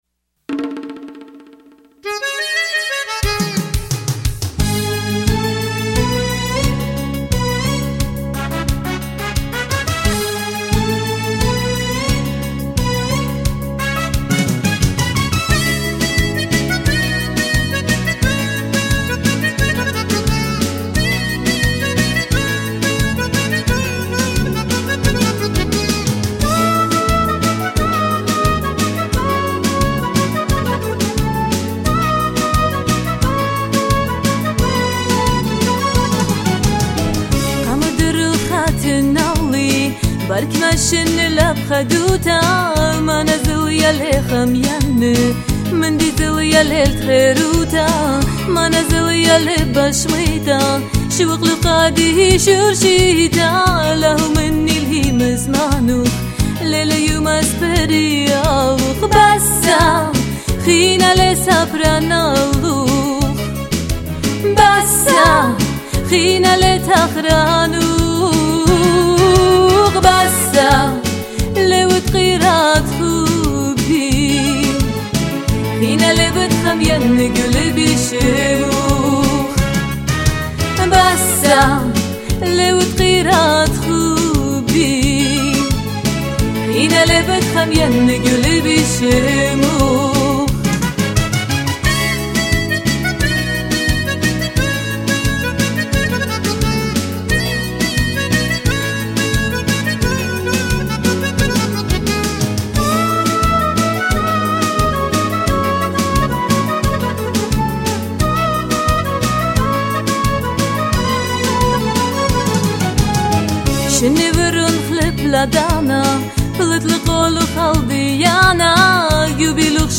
female lead singer